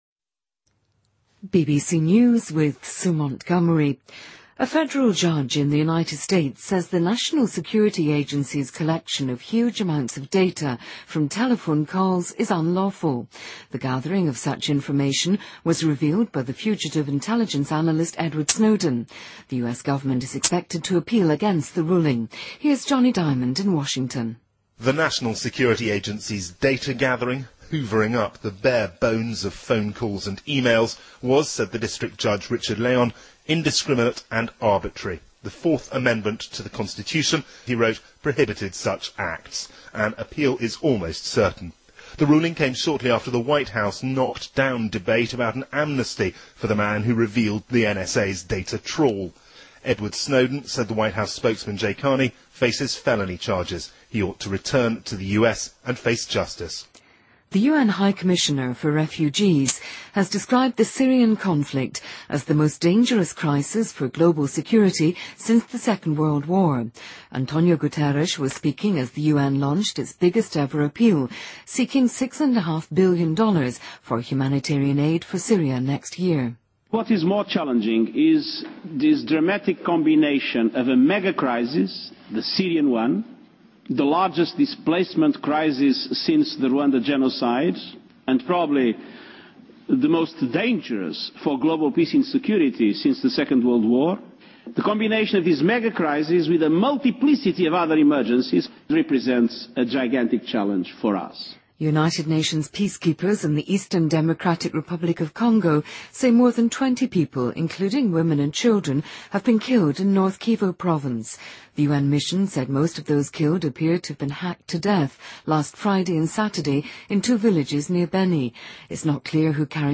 BBC news,美国联邦法官称国家安全局收集大量电话信息是非法的